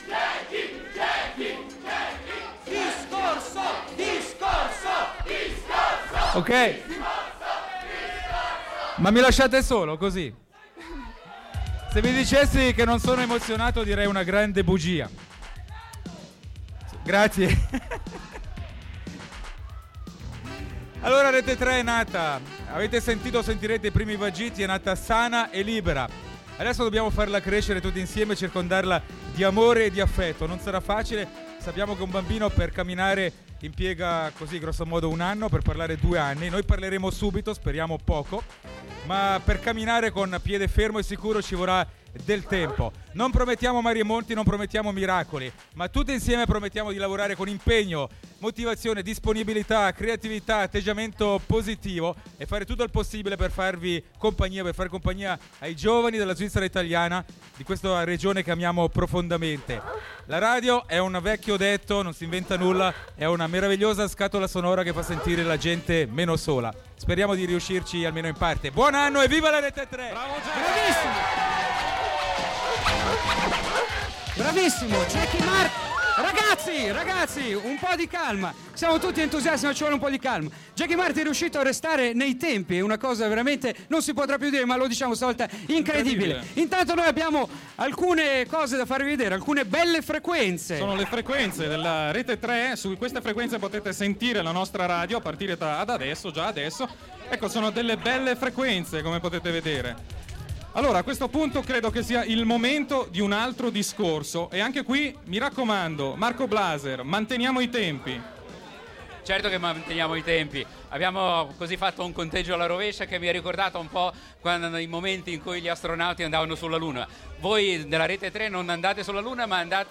È nata ufficialmente Rete Tre, la terza rete della nostra radio. E lo fa da subito con grande energia e voglia di divertire.